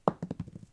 diceThrow3.ogg